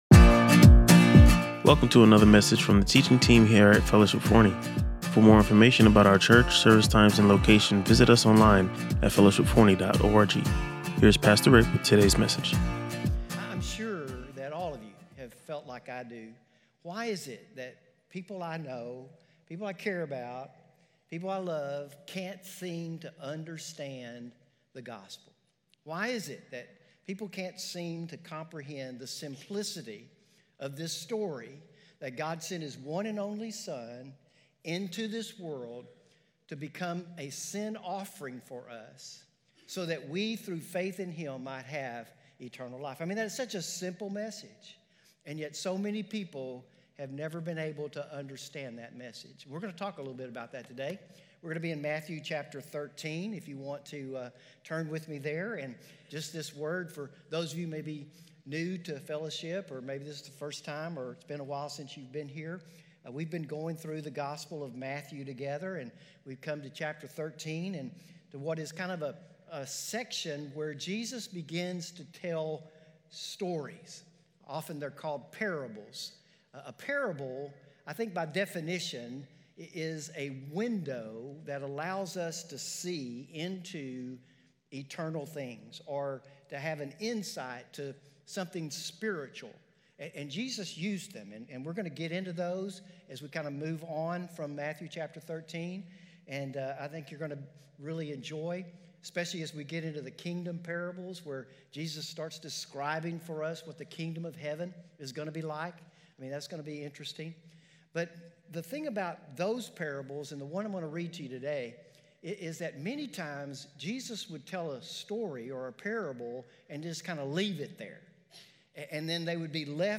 In this week’s sermon